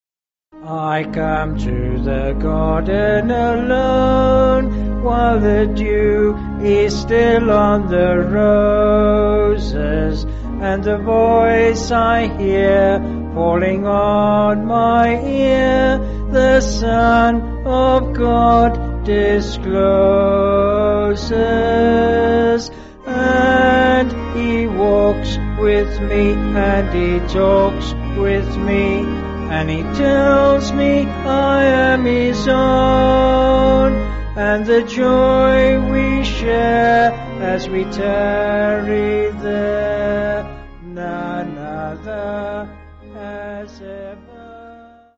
3/Ab
Vocals and Organ